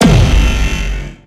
railgun-turret-gunshot-2.ogg